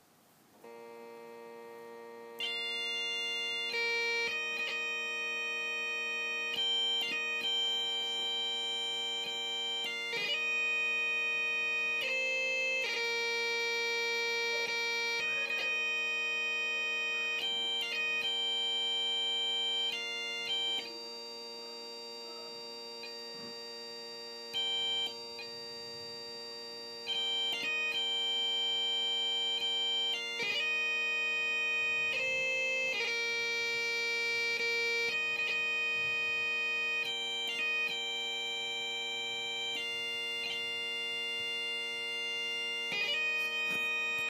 north west bagpiper